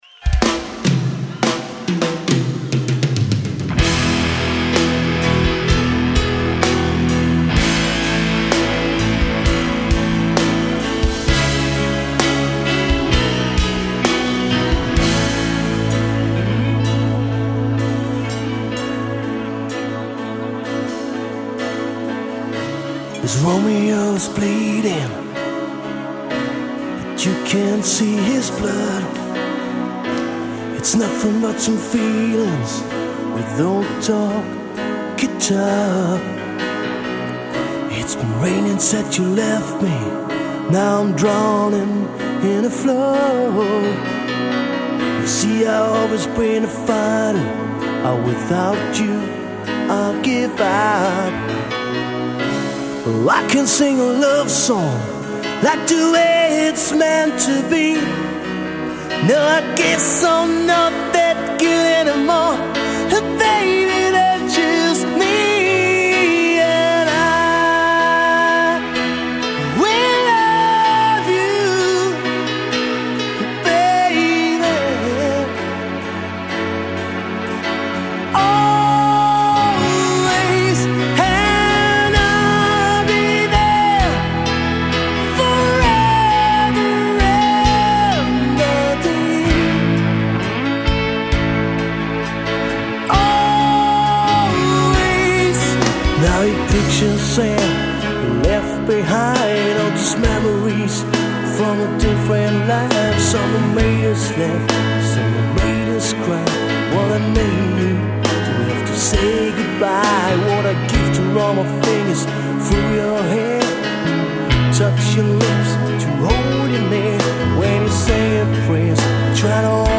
die 5-köpfige Band
handgemachten melodischen Rock
5-stimmigem Gesang